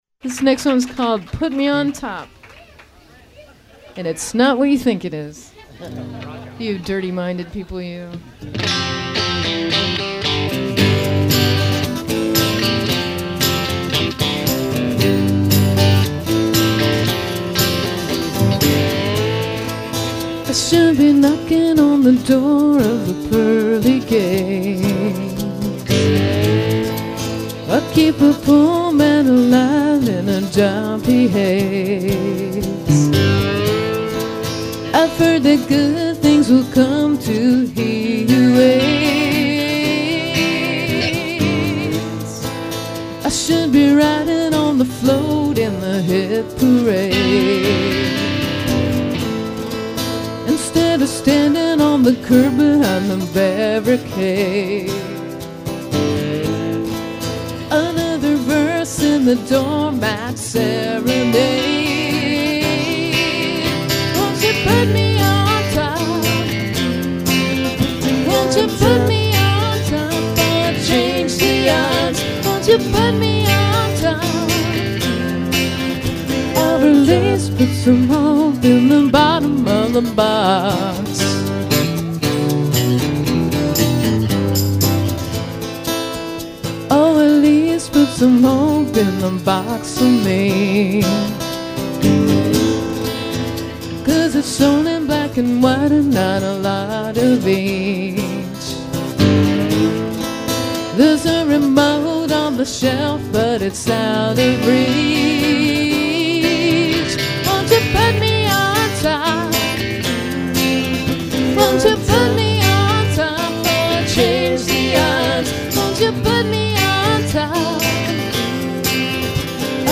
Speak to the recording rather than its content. almost unplugged broadcasting live